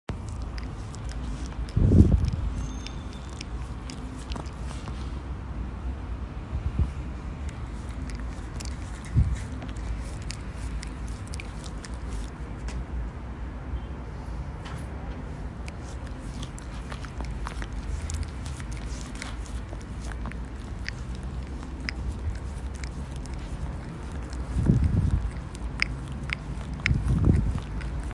Dog Licking: Instant Play Sound Effect Button